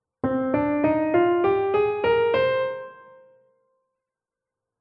Paradiddle Kapitel 1 → Natürliche Molltonleiter (Äolisch) - Musikschule »allégro«
In der Abbildung sehen wir C-Moll = die parallele Tonart von E♭-Dur also mit drei Vorzeichen:
TonleiternMollNatuerlichRein.mp3